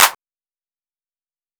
LEX Clap(1).wav